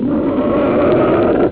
Monster2
MONSTER2.wav